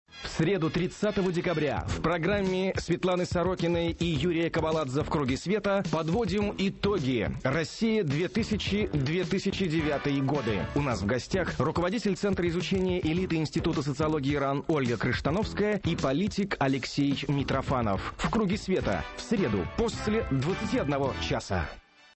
на радио «Эхо Москвы»
соведущий - Юрий Кобаладзе
Аудио: анонс –